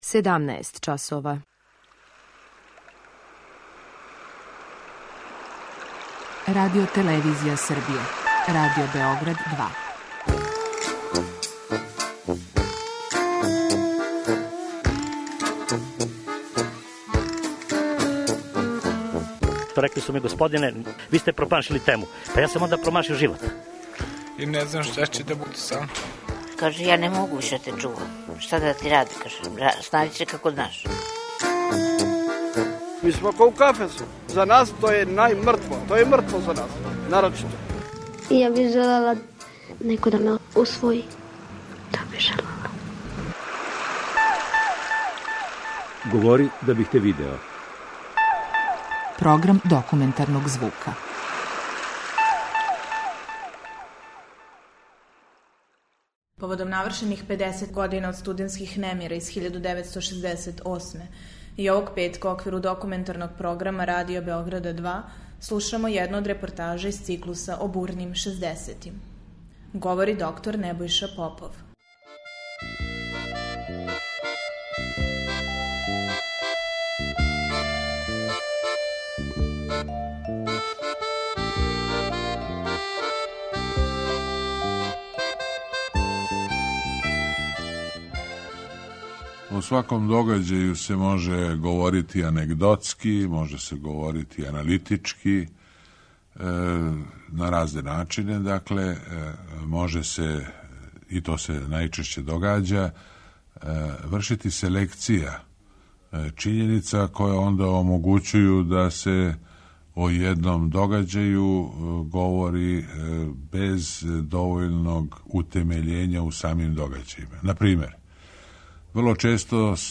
О духу побуне генерације која је искрено веровала у моћ социјализма, утицају на политику и културу, пред микрофонoм Радио Београда 2 евоцирали су своја сећања најзначајнији актери, студенти, професори Београдског универзитета и новинари.
преузми : 10.76 MB Говори да бих те видео Autor: Група аутора Серија полусатних документарних репортажа, за чији је скупни назив узета позната Сократова изрека: "Говори да бих те видео".